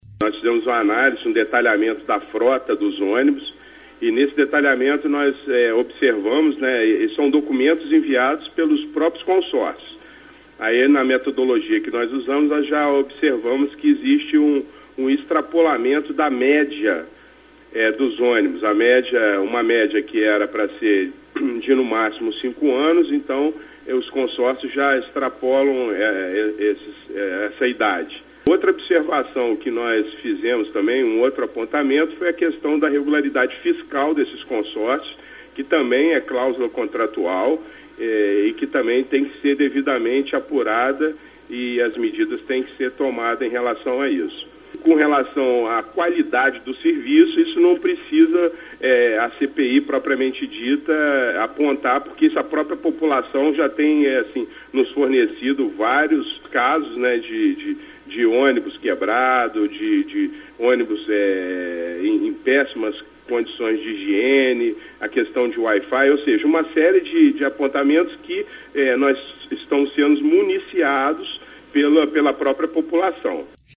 presidente da CPI, vereador Adriano Miranda (PHS)
cpi-onibus-adriano-miranda.mp3